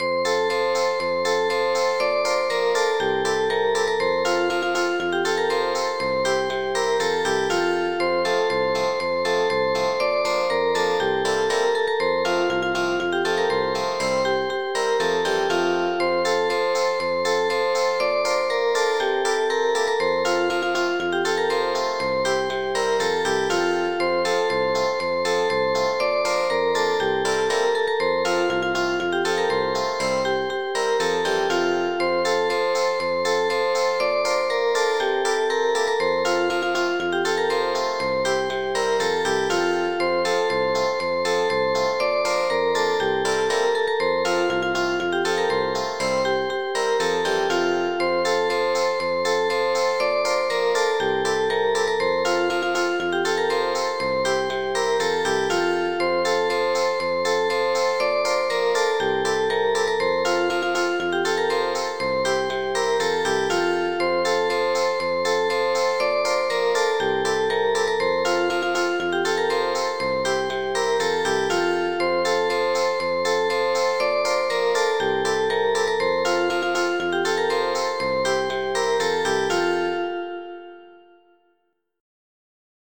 MIDI Music File
Type General MIDI